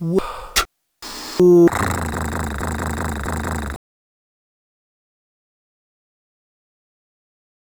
Ein poetisches Soundpartikel, lyrisch schon wegen seiner Kürze. Unter der Entscheidungsfrage schnurrt dann auch noch das animalische Behagen einer Katze, eines fraglichen Pelztiers, what furrr...
"what for?" kam mir in den sinn, während ich eine entscheidung zu treffen hatte. der beitrag sollte auch eher lautmalerisch verstanden sein, als dass er sprache abbildet.